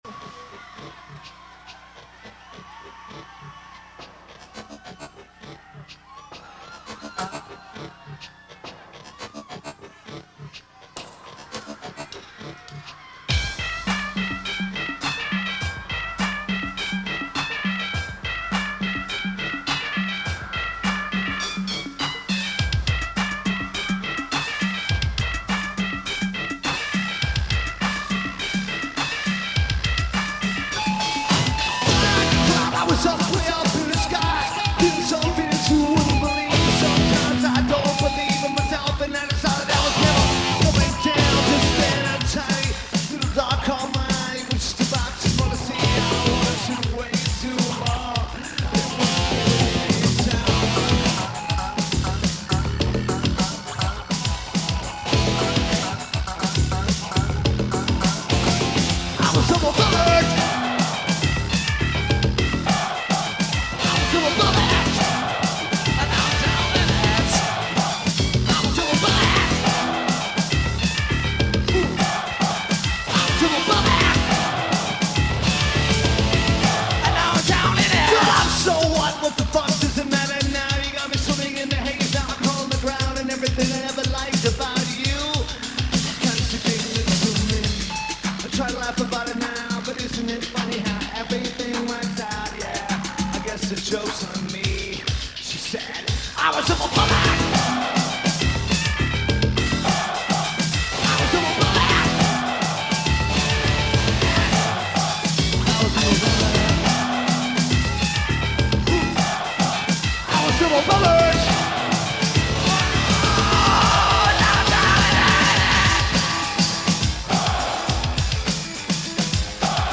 Keyboards/Programming
Drums
Bass/Guitar
Vocals/Guitar/Keyboards
Lineage: Audio - PRO (Soundboard)